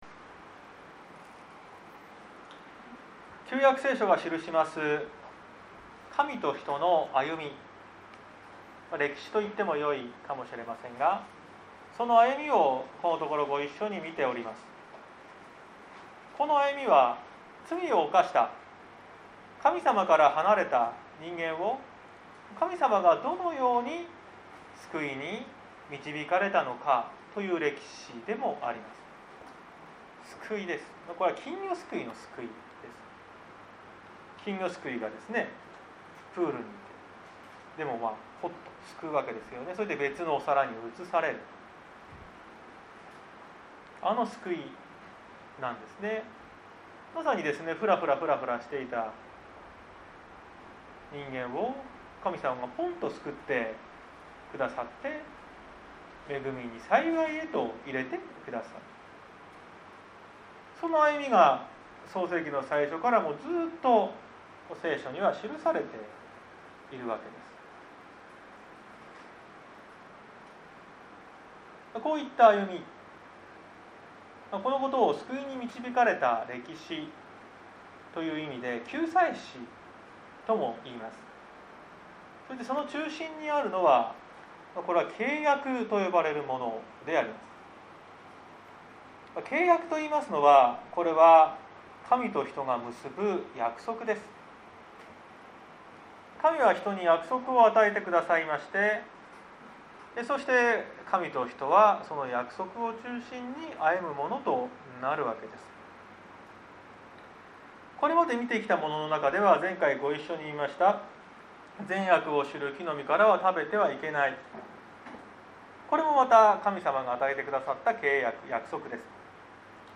2021年09月19日朝の礼拝「ノア契約」綱島教会
綱島教会。説教アーカイブ。